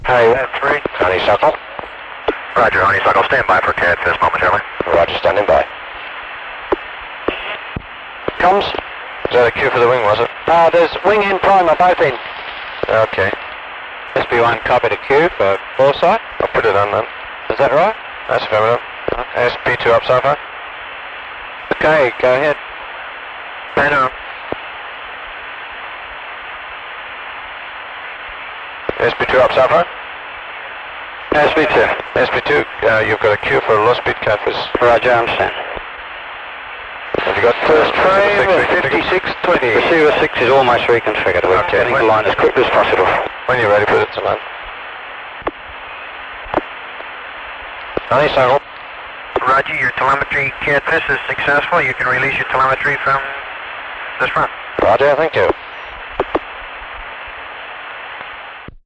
Honeysuckle’s last track of Apollo 13, as the spacecraft nears Earth on 17 April 1970.
Apollo 13 comms from Honeysuckle Network calls